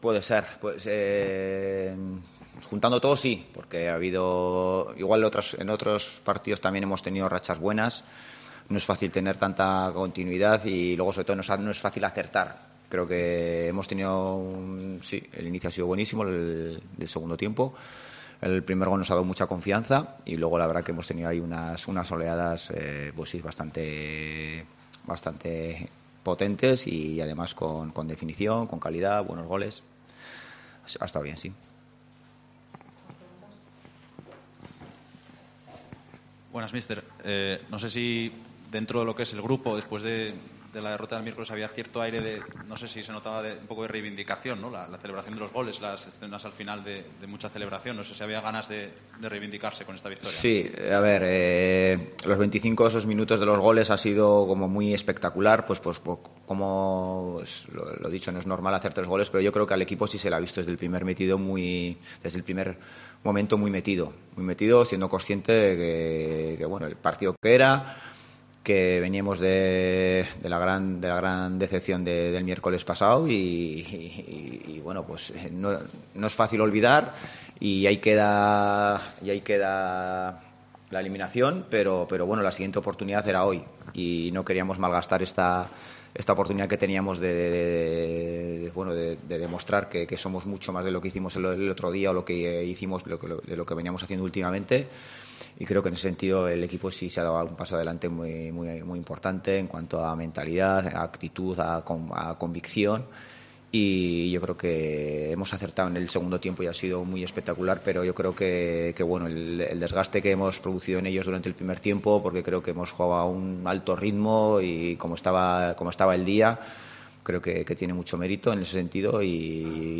Rueda de prenda